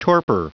Prononciation du mot torpor en anglais (fichier audio)
Prononciation du mot : torpor